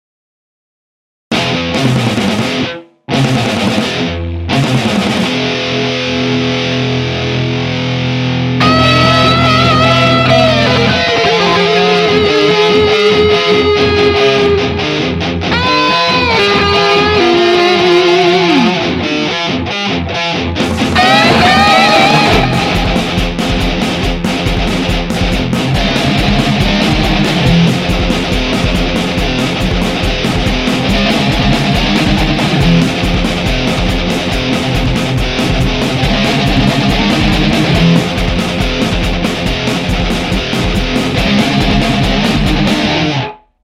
Из за моей говяной гитары все получается ужасно! Еще задержка в риге.
Как же размазывается звук гитары. Она ужасно хрипит :-( :-( :-(
Я подключаю гитару к компьютеру.